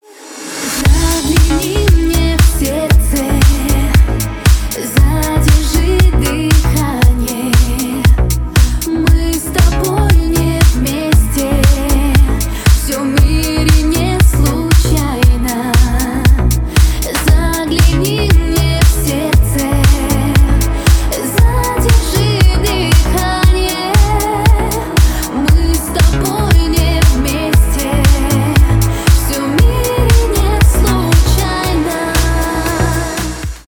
красивый женский голос , клубные
deep house